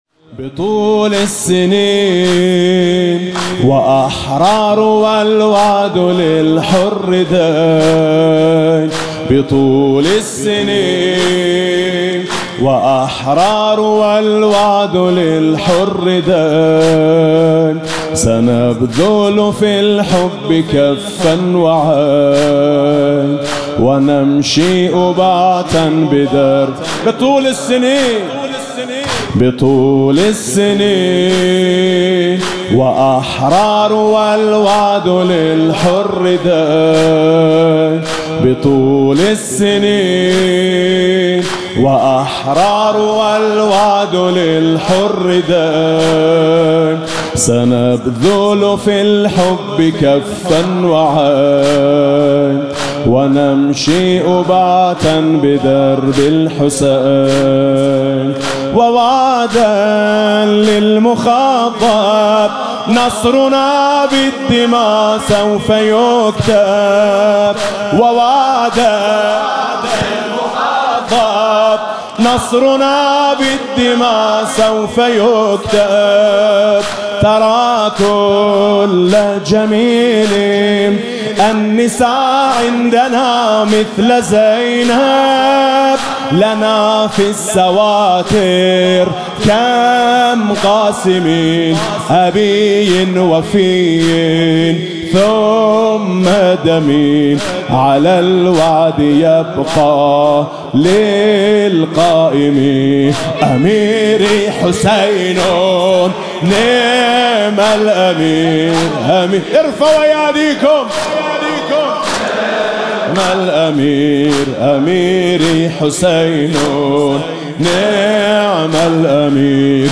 مناسبت: قرائت دعای ۱۴ صحیفه سجادیه و عزاداری ایام شهادت حضرت زهرا (س)
با نوای: حاج میثم مطیعی
بدمّ الأمین (مداحی عربی از زبان مردم مقاوم لبنان)